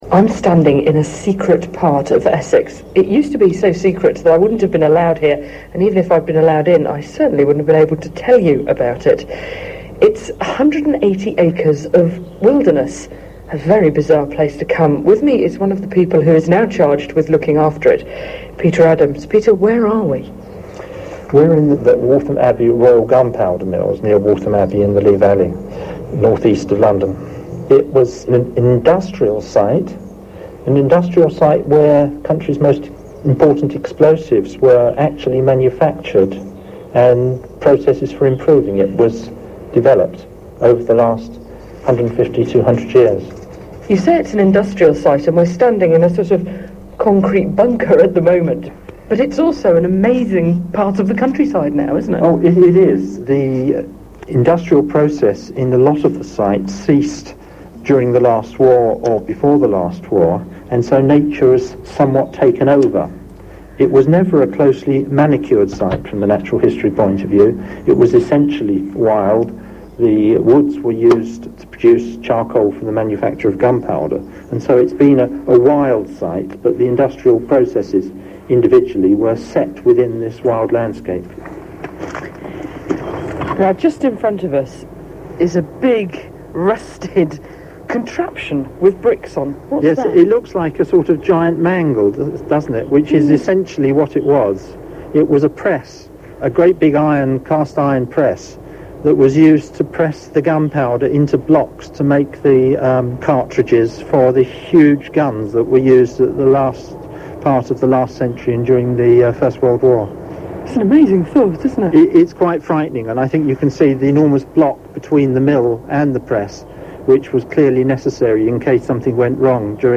WAOH - Waltham Abbey Oral History
Interview